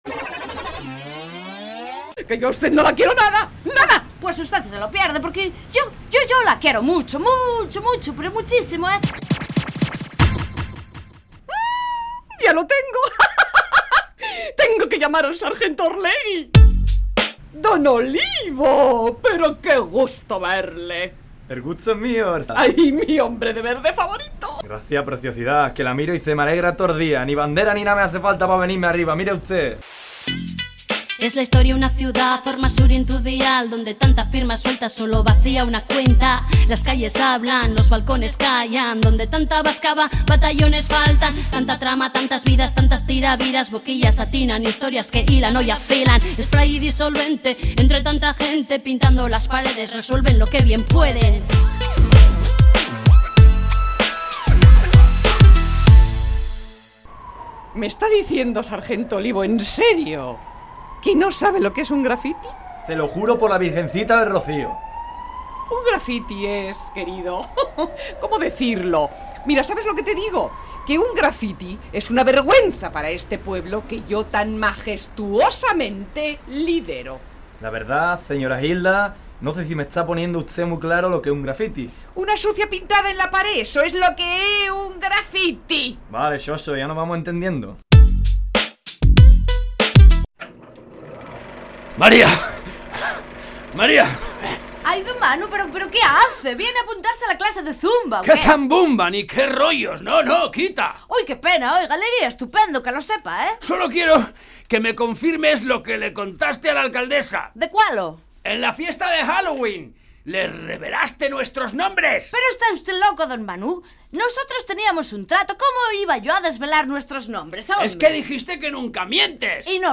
Entrega número 22 de la Radio-Ficción “Spray & Disolvente”